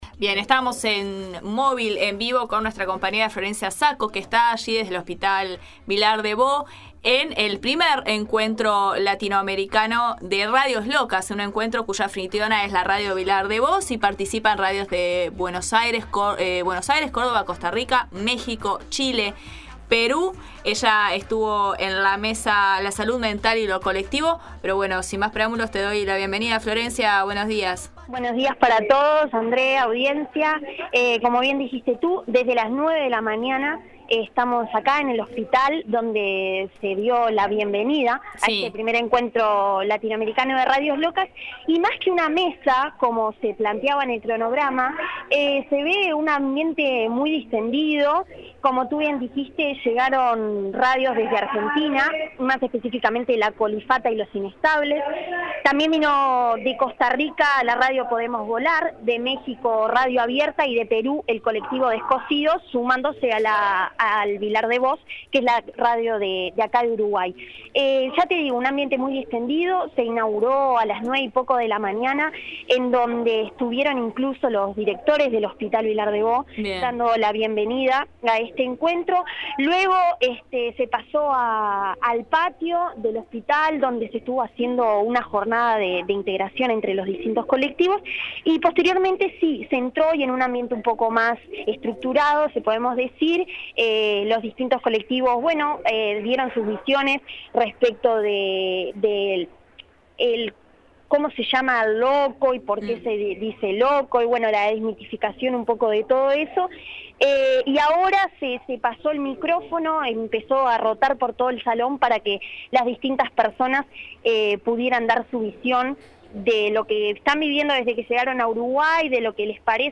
Audio: Movil desde el I Encuentro Latinoamericano de Radios Locas